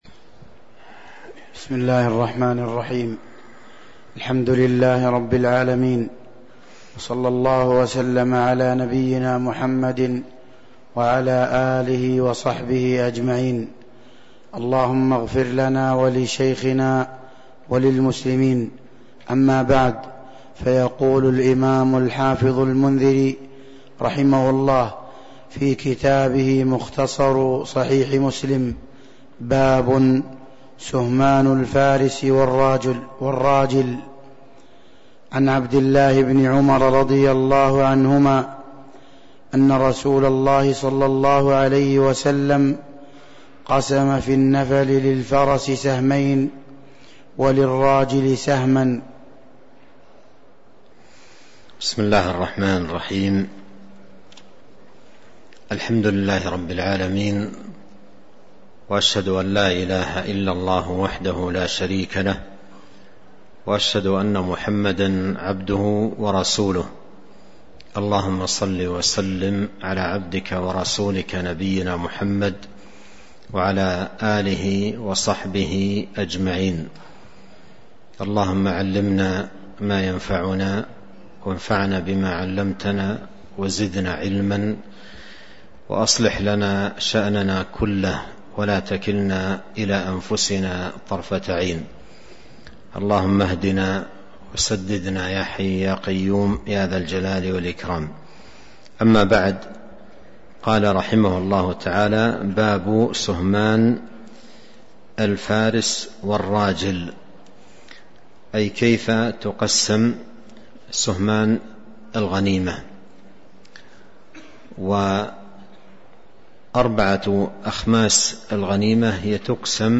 تاريخ النشر ١٨ ربيع الثاني ١٤٤٣ هـ المكان: المسجد النبوي الشيخ